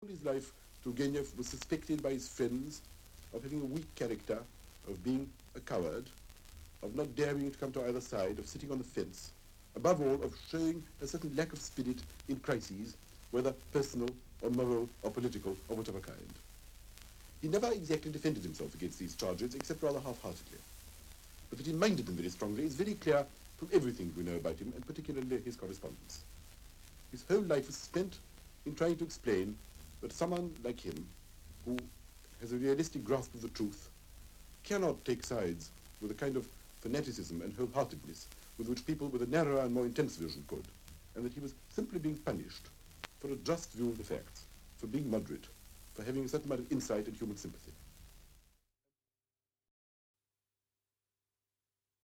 The earliest I have available are four or five years younger, and I have chosen one from 1957, when Berlin read on the radio his translation of Turgenev’s short autobiographical story, ‘A Fire at Sea’, in which Turgenev relates how he tried to bribe a sailor for a place in a lifeboat when a ship he was travelling on caught fire. The story was preceded by an introduction in which Berlin discussed the reluctance of a person like Turgenev with liberal sensibilities to take up firm positions.